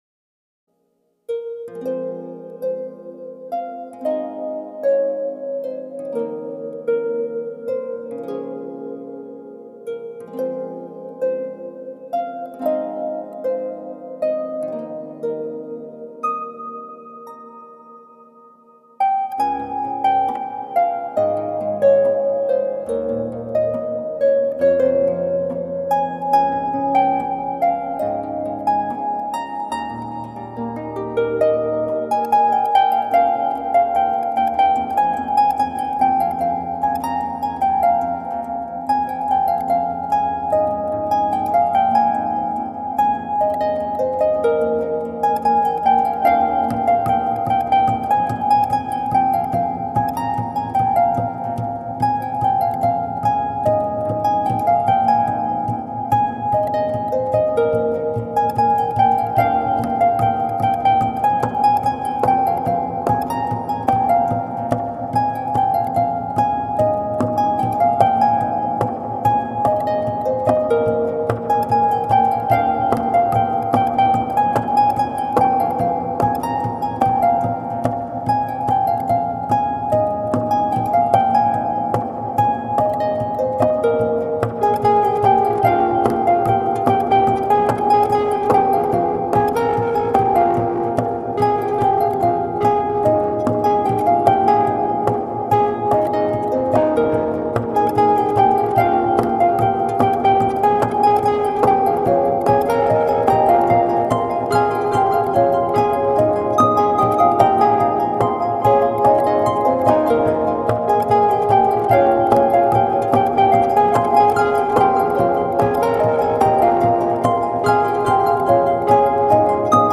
• Unique live looping & electro-acoustic harp covers
(live looping cover)